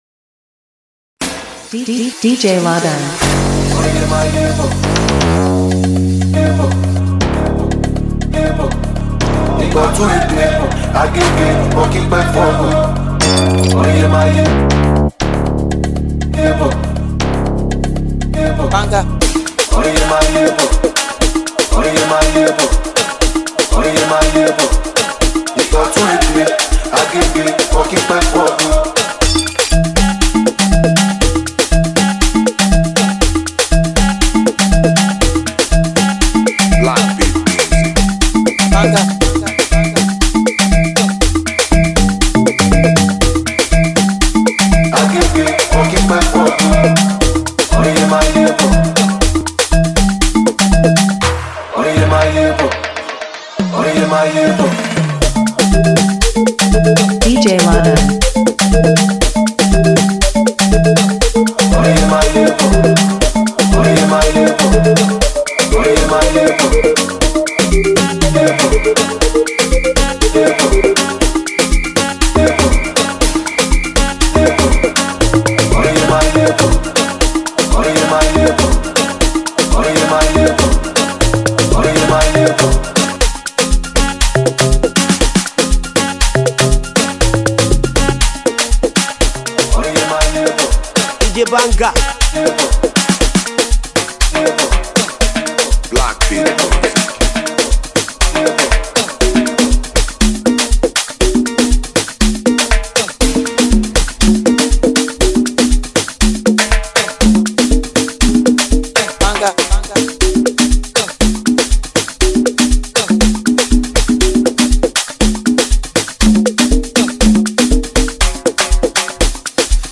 Afrobeats music singer